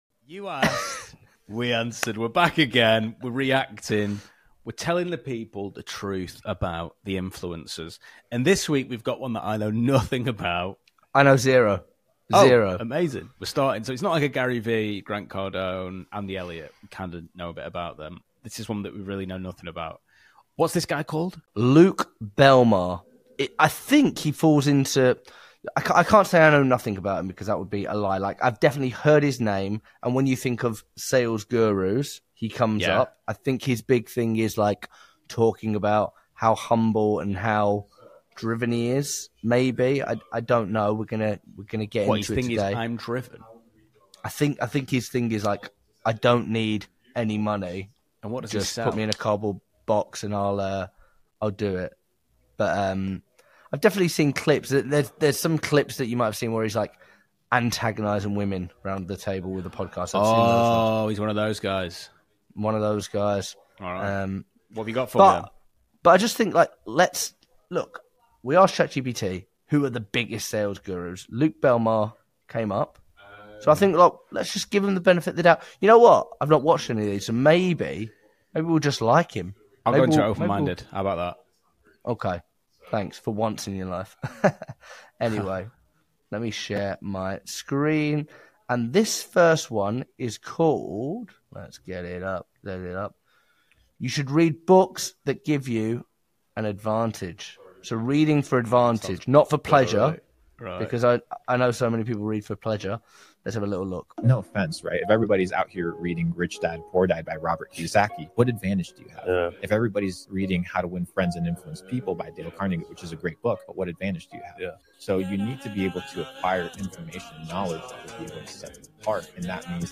Just two guys dissecting the hype, the hustle, and the hard truths behind influencer capitalism.